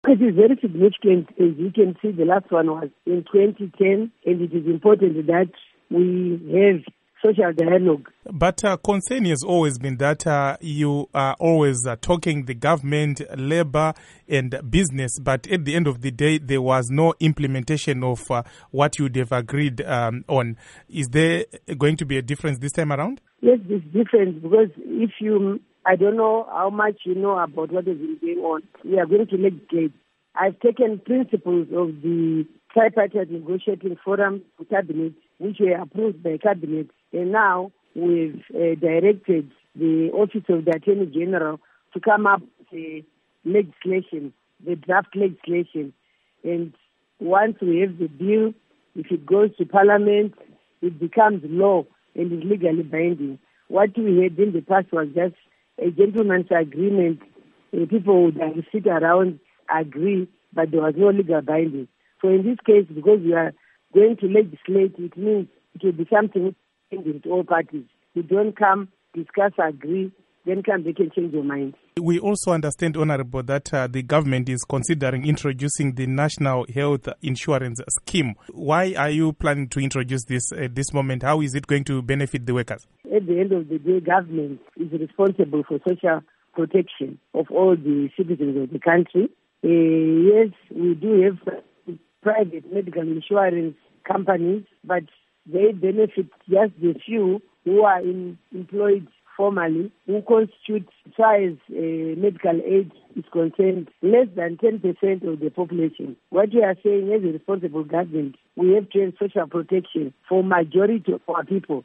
Interview With Priscah Mupfumira on Tripartite Negotiations, New Insurance